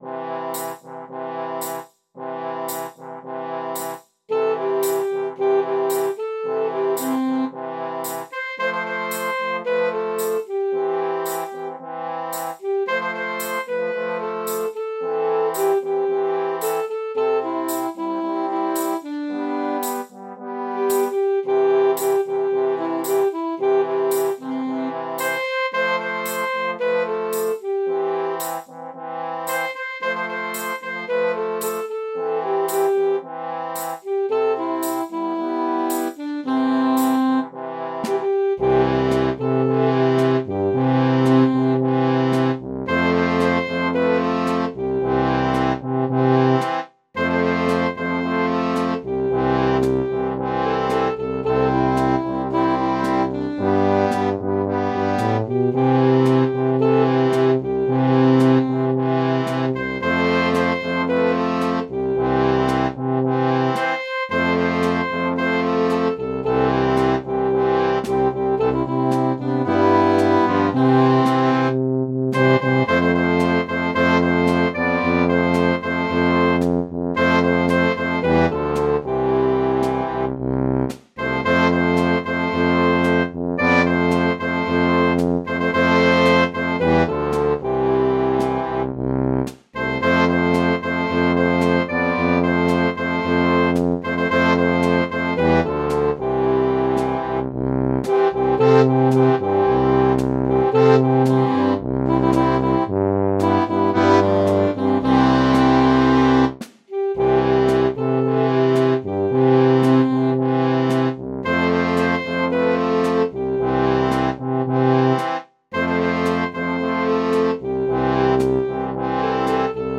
Tempo = about 112 BPM Key of C concert.